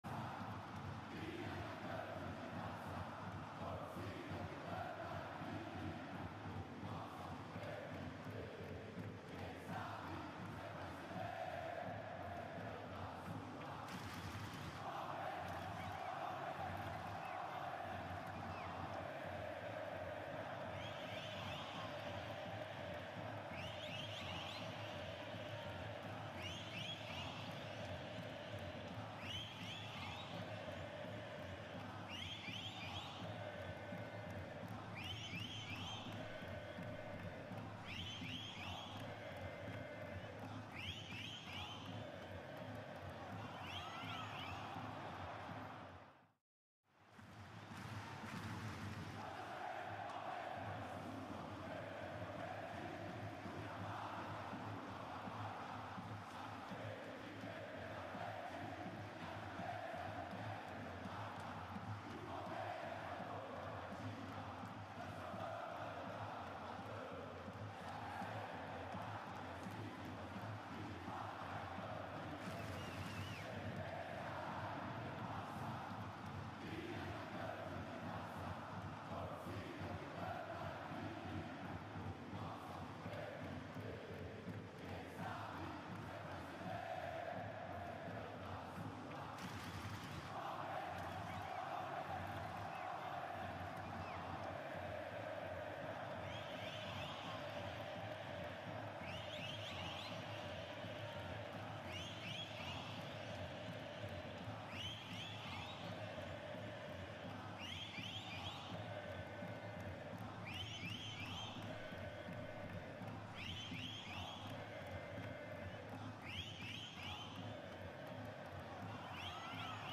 Um dia depois de assumir oficialmente a presidência do Palmeiras, Leila Pereira concedeu entrevista coletiva nesta quinta-feira (16), na sala de imprensa da Academia
COLETIVA-DA-PRESIDENTE-LEILA-PEREIRA.mp3